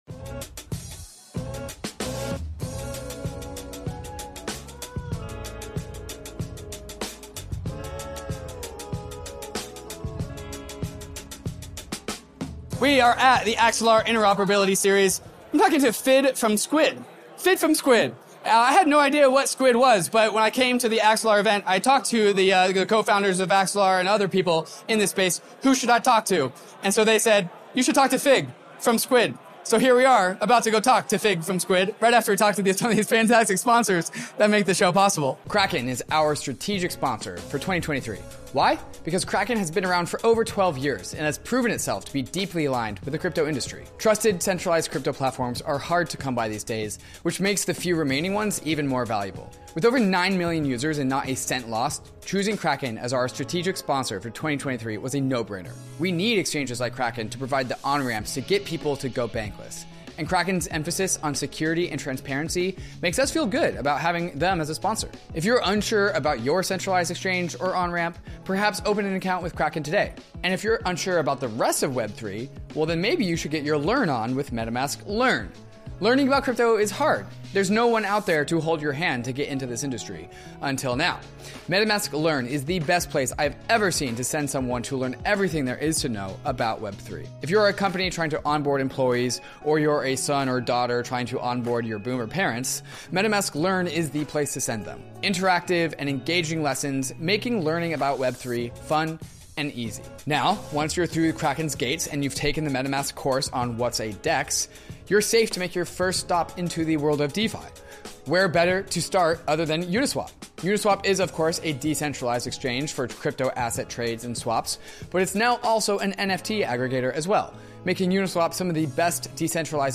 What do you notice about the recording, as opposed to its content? ETHDenver 2023 Interview #5 Sometimes, the frontier is at a crypto conference.